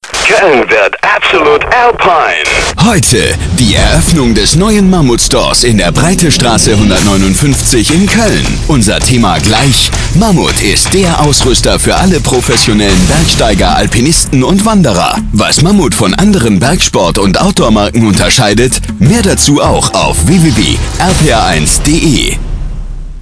Besondere Inhalte der Spots sind O-Töne von Basecamp-Experten und Teilnehmern sowie themenbezogene Fragestellungen verknüpft mit aktuellen Informationen zur Veranstaltung.
Langer Radiospot bei RPR1: